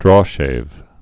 (drôshāv)